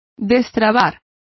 Complete with pronunciation of the translation of unfetter.